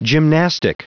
Prononciation du mot gymnastic en anglais (fichier audio)
Prononciation du mot : gymnastic
gymnastic.wav